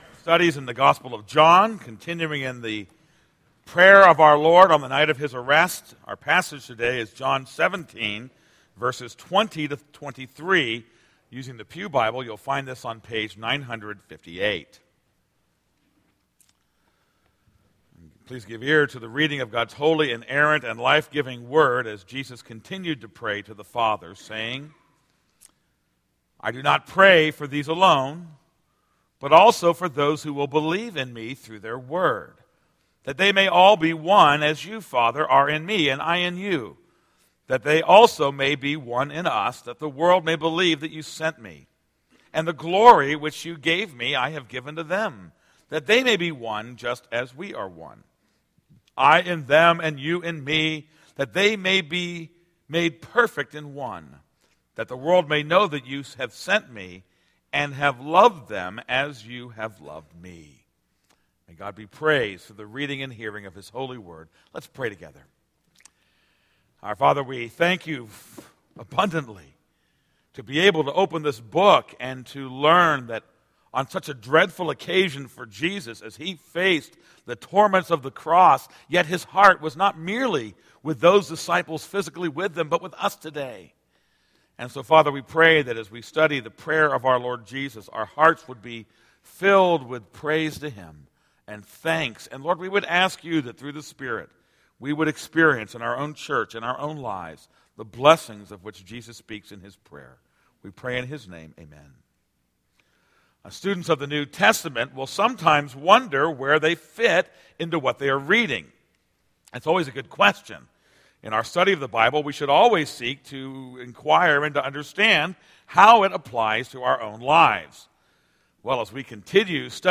This is a sermon on John 17:20-23.